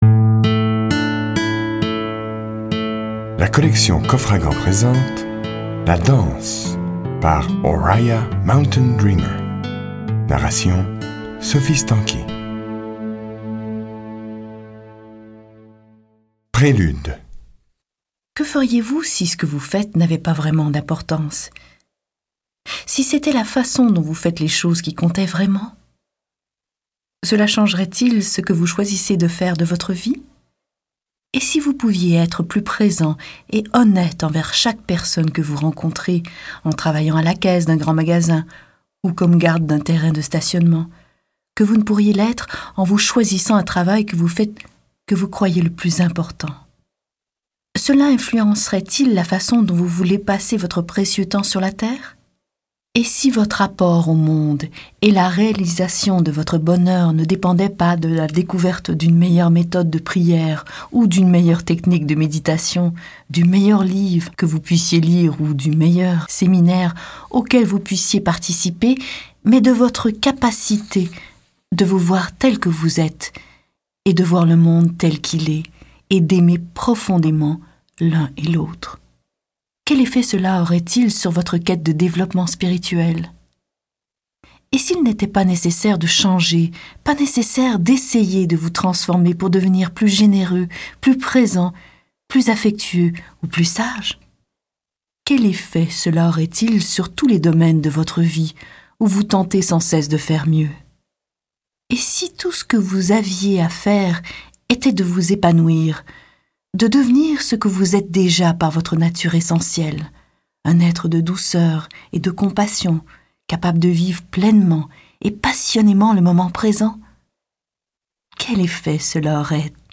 Click for an excerpt - La danse de Oriah Mountain Dreamer